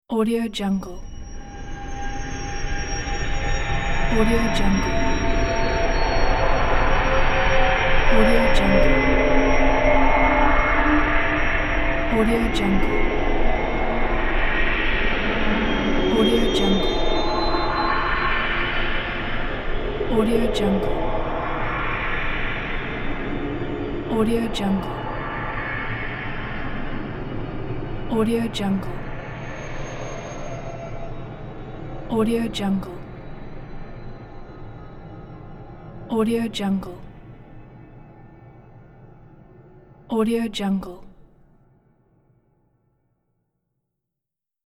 دانلود افکت صوتی انتقال جادویی